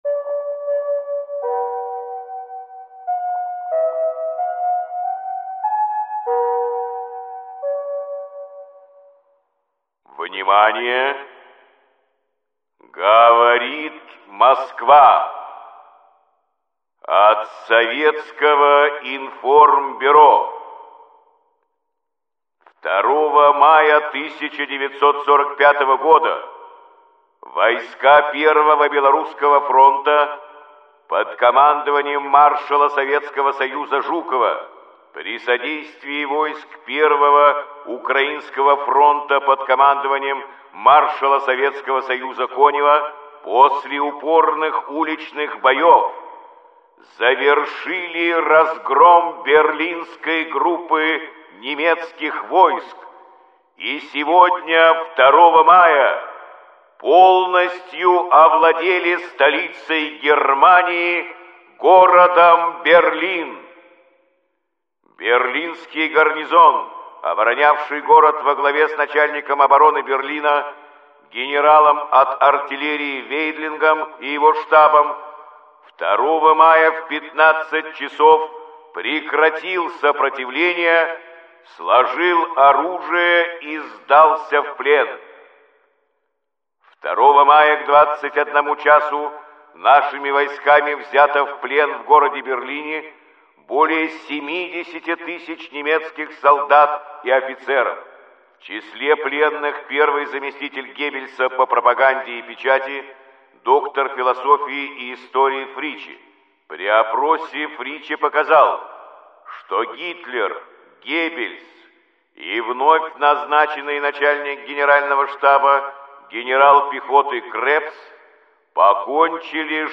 Каждый день – это хронология самых важных событий и подвигов героев Великой Отечественной войны, рассказанная в сообщениях СОВИНФОРМБЮРО голосом Юрия Борисовича Левитана.